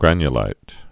(grănyə-līt)